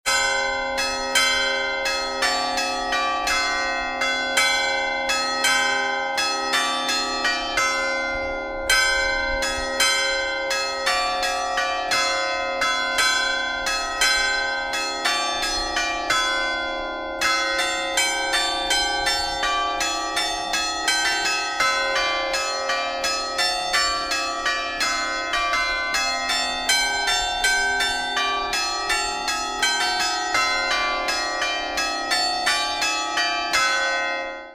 Il tutto su cinque campane fuse tra il XVIII e il XIX secolo, sopravvissute alle vicissitudini quotidiane e ai conflitti bellici.
«Le Voci della Coltura» è un cd dedicato al repertorio musicale campanario per cinque campane ed è stato registrato a Lenna sul campanile del Santuario della Coltura.
Traccia 7 – Inno religoso – 35 sec.